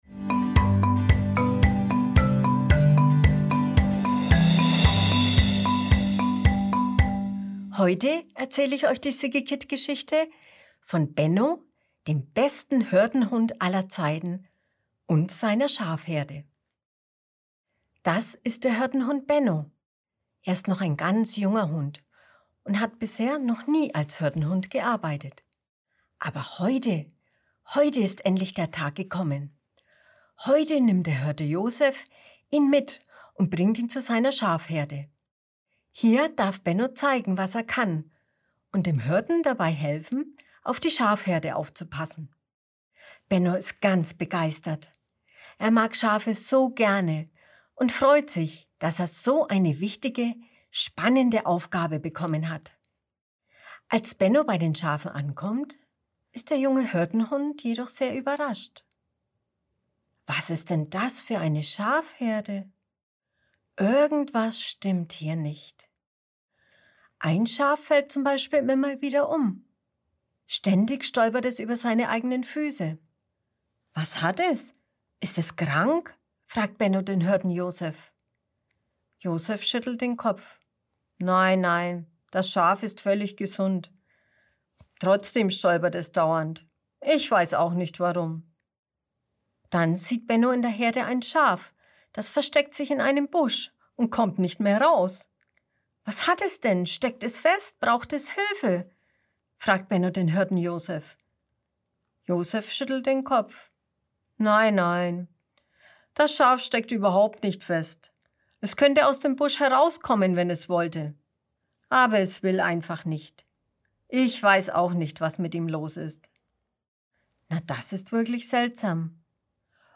Mai 2021 Kinderblog Vorlesegeschichten Heute erzählen wir dir die Geschichte vom besten Hirtenhund aller Zeiten.